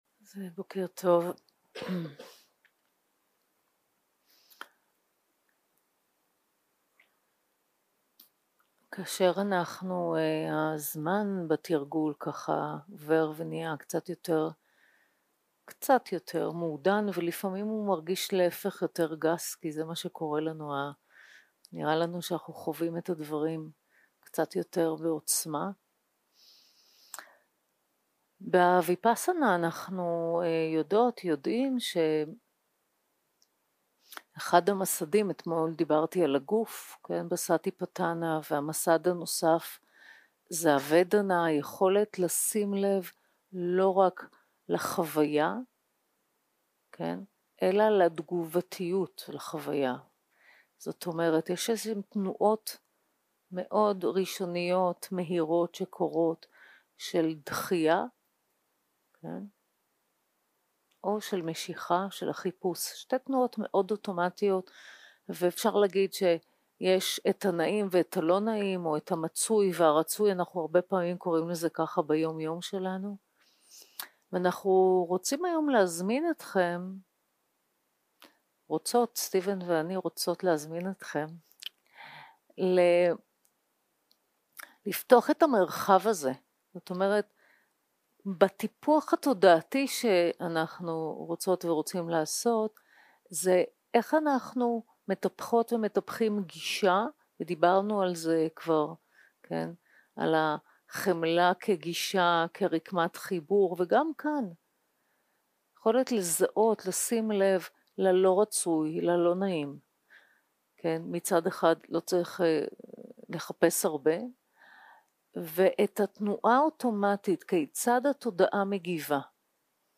יום 3 - הקלטה 4 - בוקר - הנחיות למדיטציה - טיפוח גישה של חמלה לחוויה 0:00 0:00 סוג ההקלטה: סוג ההקלטה: שיחת הנחיות למדיטציה שפת ההקלטה: שפת ההקלטה: עברית